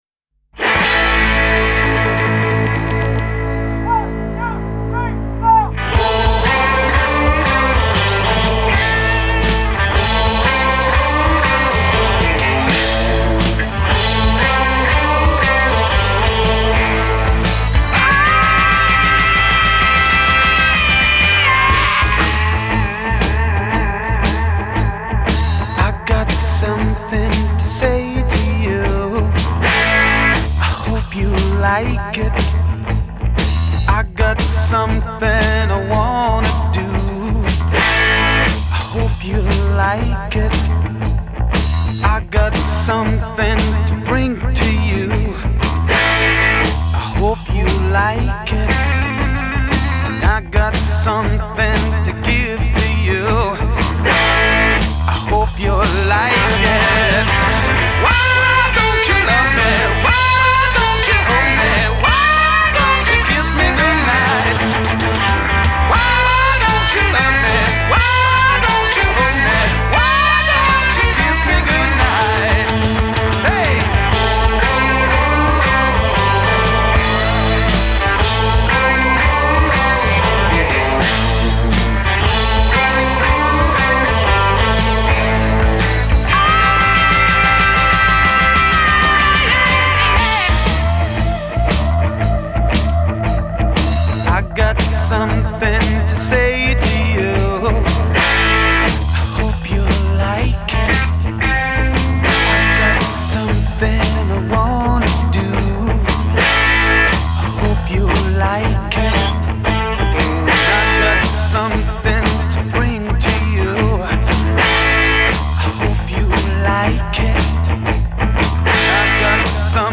Genre: Rock, Glam Rock